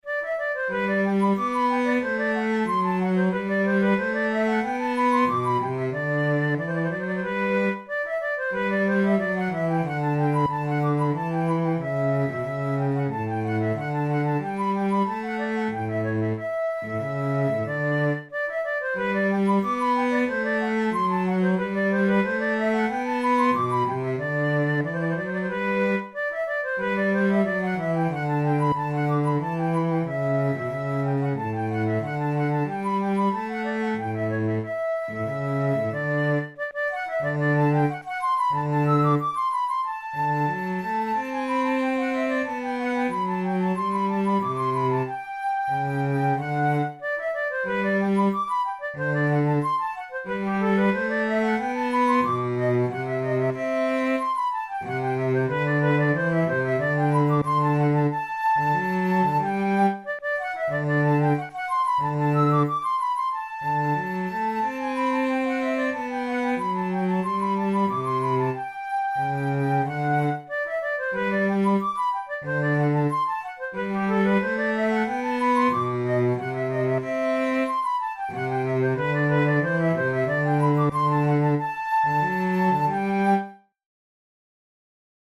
InstrumentationFlute and bass instrument
KeyG major
Time signature4/4
Tempo92 BPM
Classical, Written for Flute
with bass accompaniment